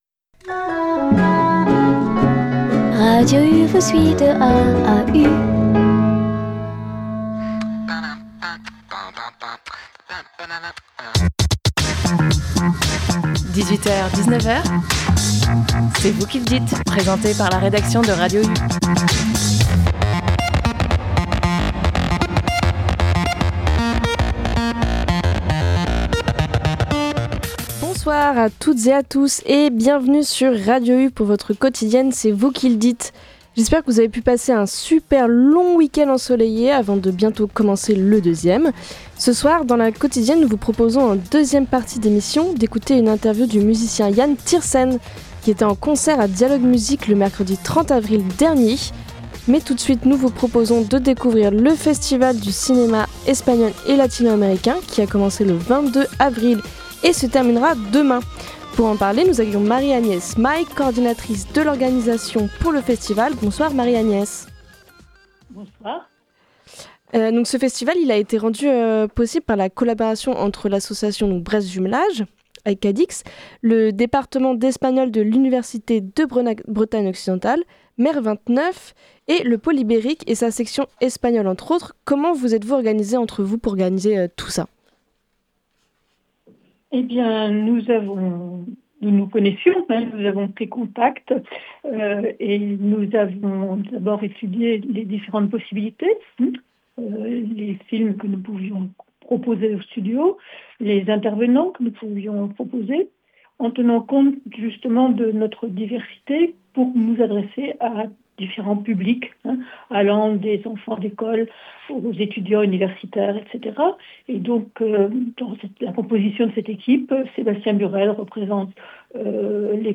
Puis, nous avons terminé notre quotidienne avec une interview du compositeur Yann Tiersen avant son concert gratuit à Dialogues Musique.